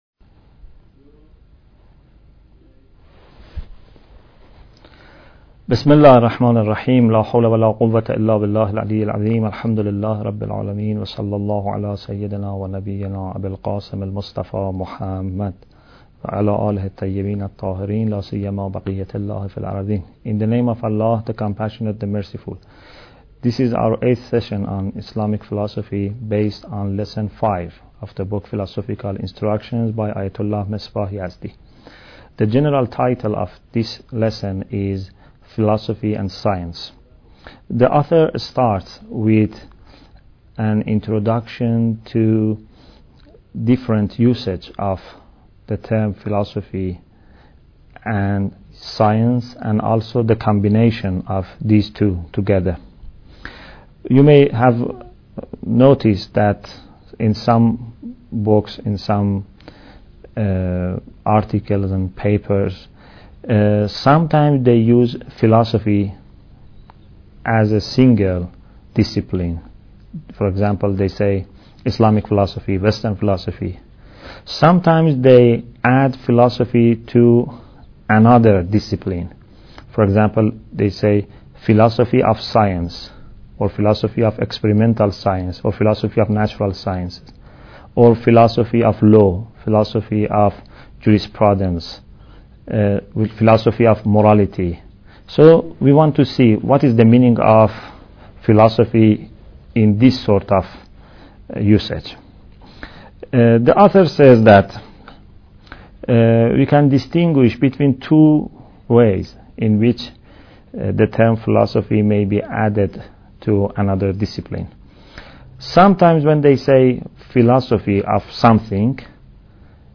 Bidayat Al Hikmah Lecture 8